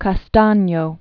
(kä-stänyō), Andrea del 1423-1457.